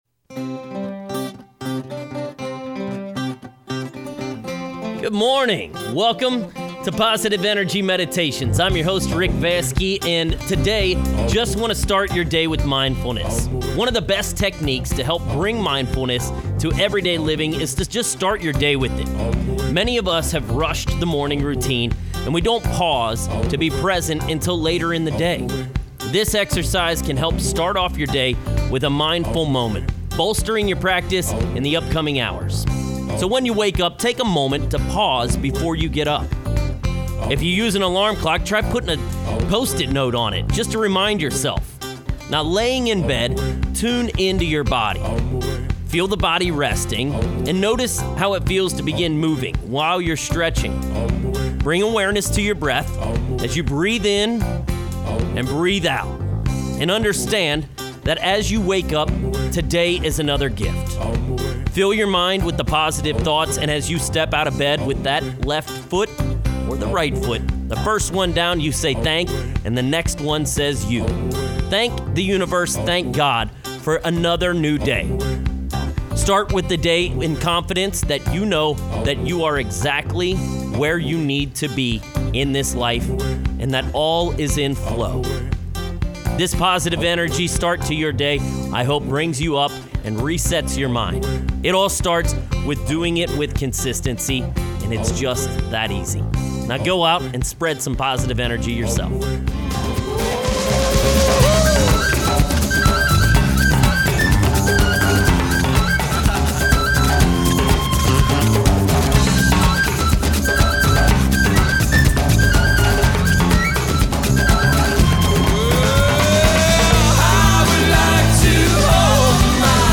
Morning Affirmation
Positive Energy Meditation - Morning WAKE UP.mp3